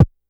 Kick_108.wav